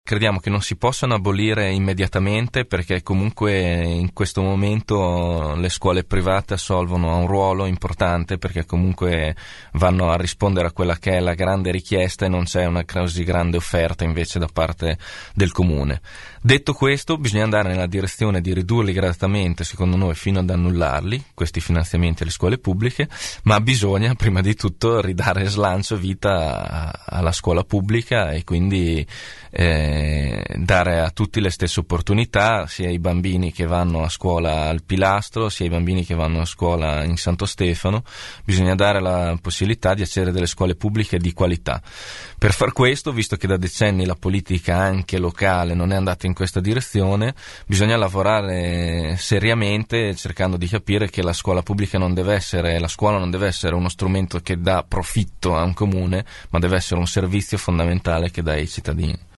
ospite dei nostri studi